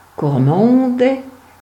Gurmels (German) or Cormondes (French: [kɔʁmɔ̃d], Franco-Provençal: [kɔʁˈmɔ̃dɛ]
Frp-greverin-Kormondè.ogg.mp3